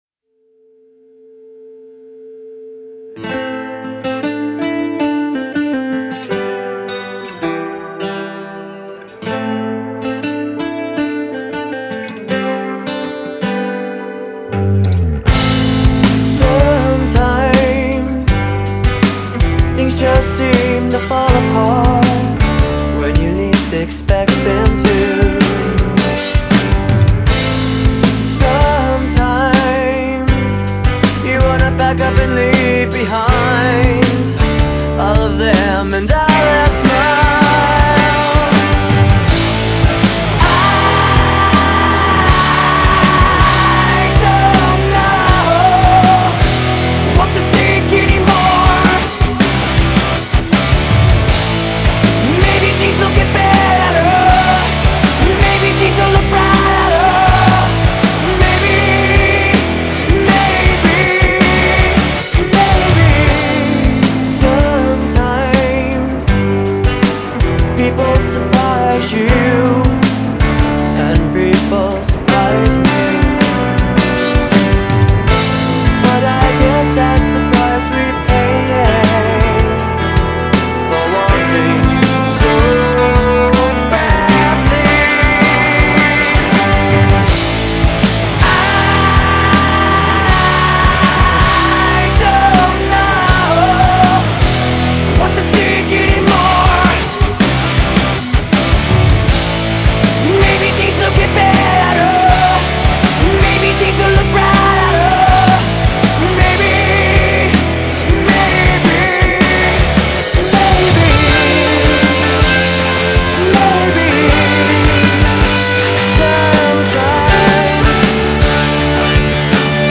Rock grunge